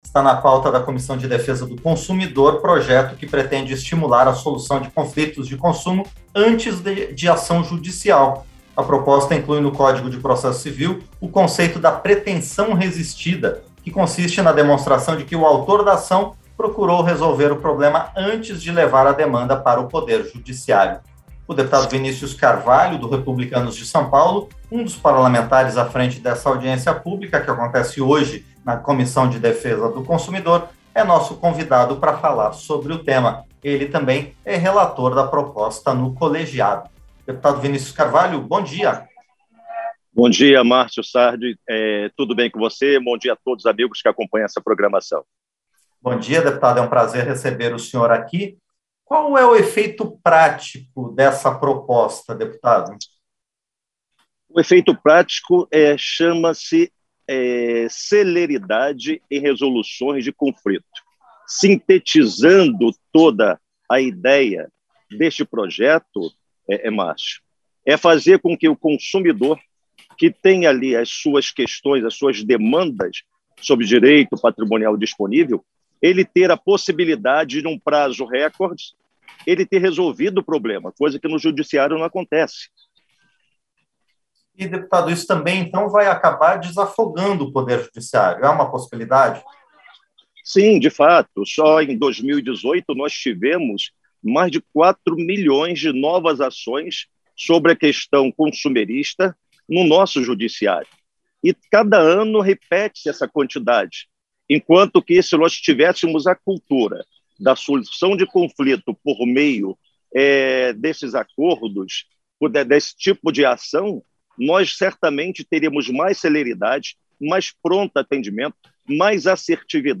Entrevista - Dep. Vinicius Carvalho (REP-SP)